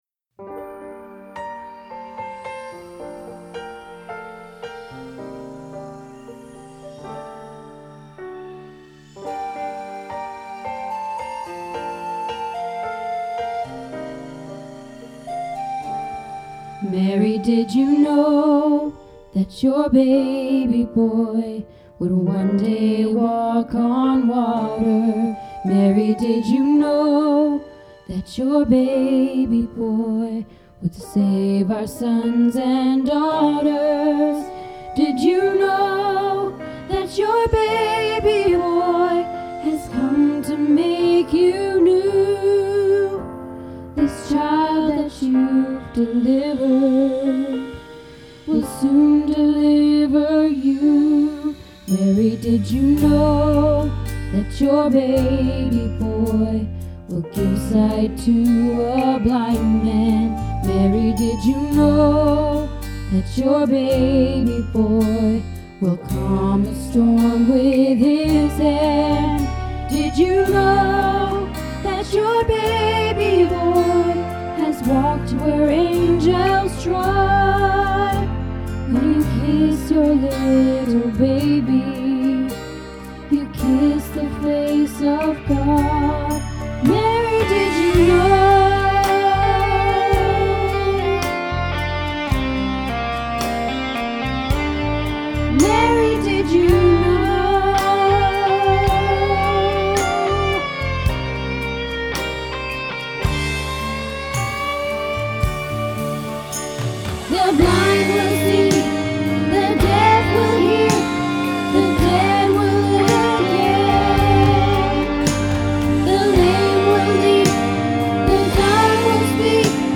Mary Did You Know - Alto